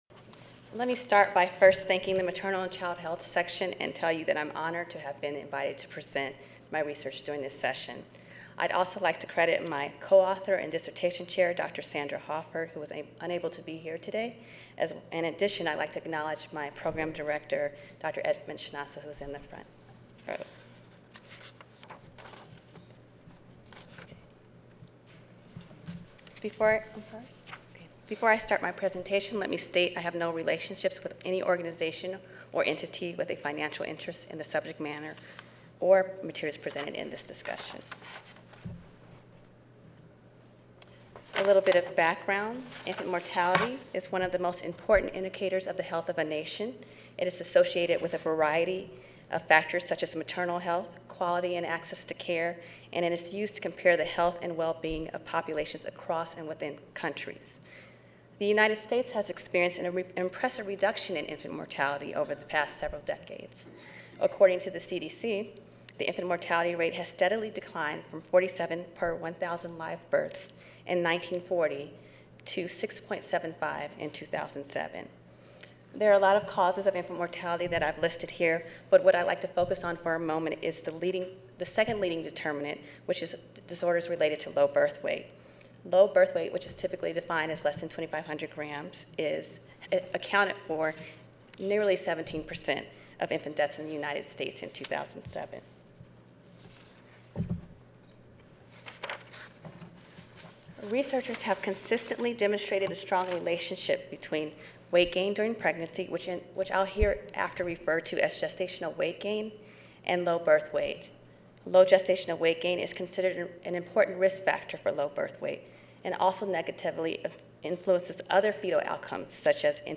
In this session, students disseminate findings from their independent or collaborative research projects that investigate a variety of maternal and child health issues, such as maternal gestational weight gain and pregnancy and infant outcomes, relationship of WIC participation to breastfeeding, and impacts of air pollution and maternal depression on child health.